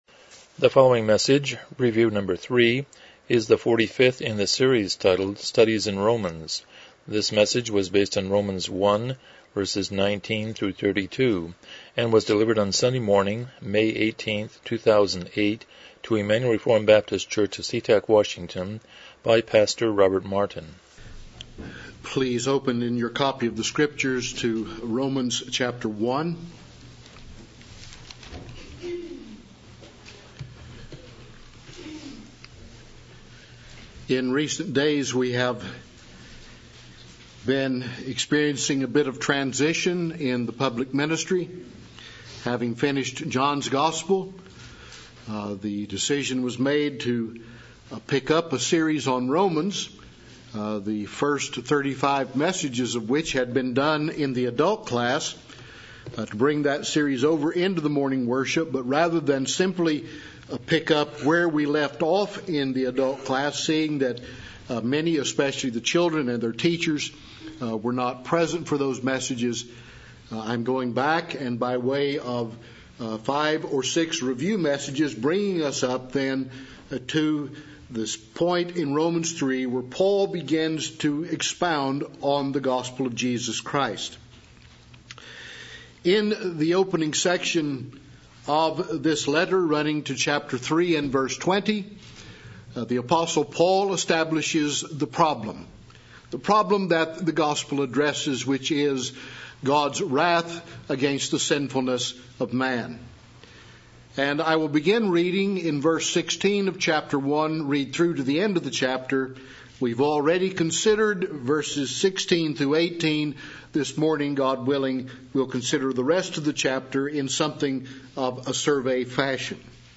Passage: Romans 1:19-32 Service Type: Morning Worship « 02 Lessons from Creation